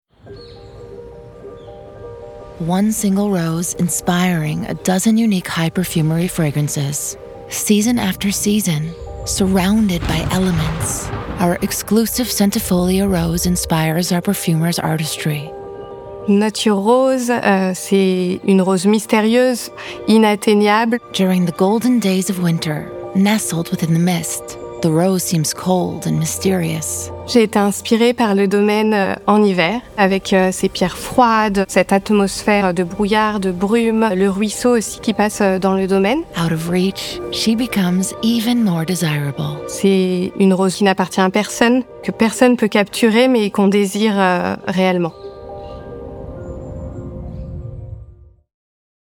Female
Approachable, Assured, Confident, Conversational, Corporate, Energetic, Engaging, Natural
Neutral Transatlantic United States, Southern United States, and neutral Canadian Transatlantic (native), French
dry studio read.mp3
Microphone: Rode Nt1-A